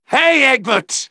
synthetic-wakewords
ovos-tts-plugin-deepponies_Medic_en.wav